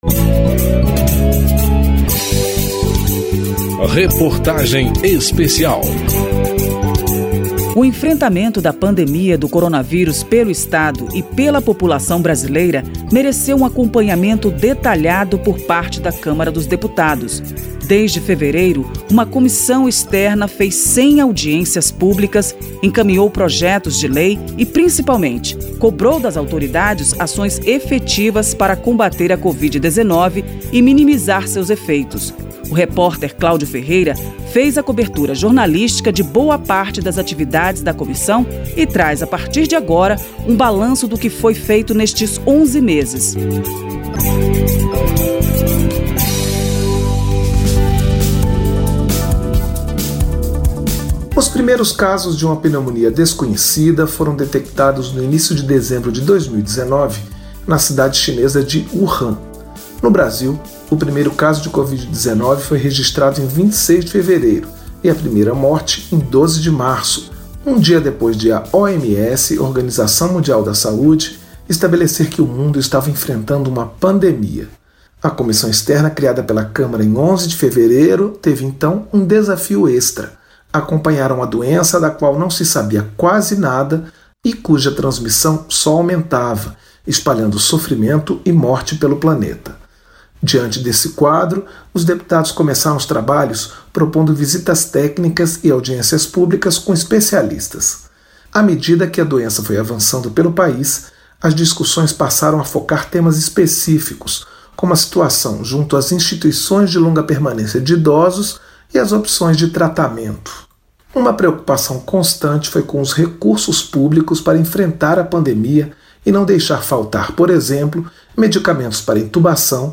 Reportagem Especial
Ouvidos neste capítulo: ministro da Saúde, Eduardo Pazuello; ex-ministro da Saúde Nelson Teich; governador do Rio Grande do Sul, Eduardo Leite; deputado Dr. Luiz Antonio Teixeira Jr. (PP-RJ) ; deputada Carmen Zanotto (Cidadania-SC) ; deputado Alexandre Padilha (PT-SP) .